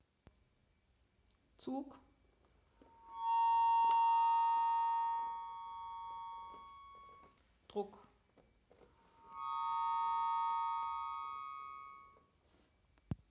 Andere Töne schwingen im 4-Fuß-Chor mit
Seit kurzer Zeit schwingen, sobald der 4-Fuß-Chor mitspielt, andere Töne mit. Auch wenn ich z.B. auf Zug und Druck selbst keine Töne spiele, den Luftknopf nicht drücke und den Balg sozusagen mit "Gewalt" drücke oder ziehe. Das klingt dann so wie in der Datei, die ich angehängt habe.